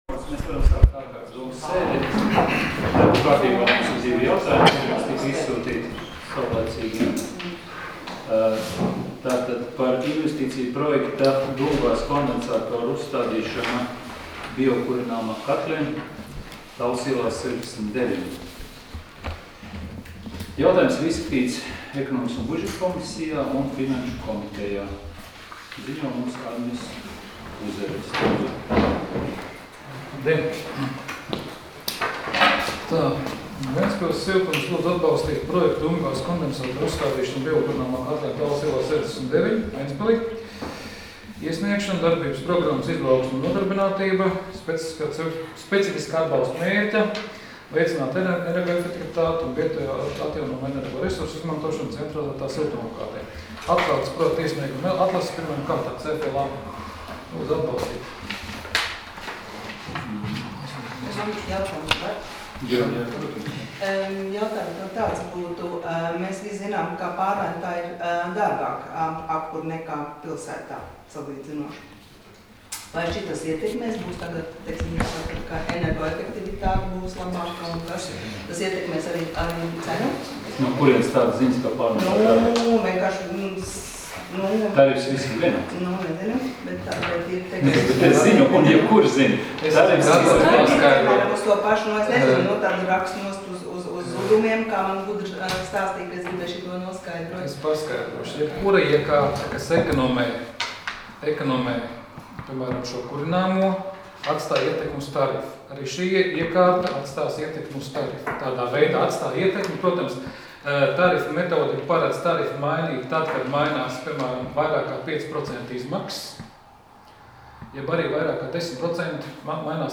Domes sēdes 14.07.2017. audioieraksts